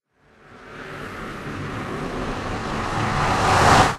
1.21.4 / assets / minecraft / sounds / portal / trigger.ogg